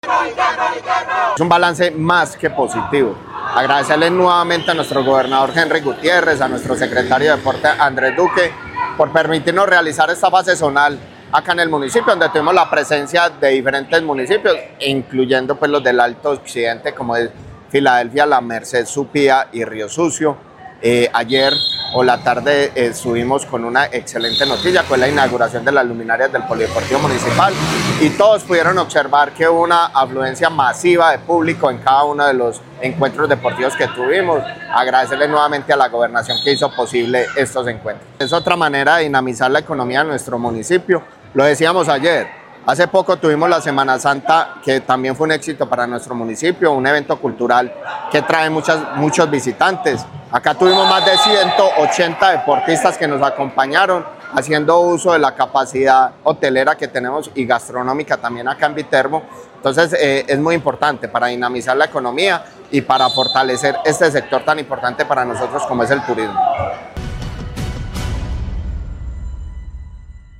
Néstor Javier Ospina Grajales, alcalde de Viterbo.
Nestor-Javier-Ospina-Grajales-Alcalde-de-Viterbo-Juegos-Departamentales.mp3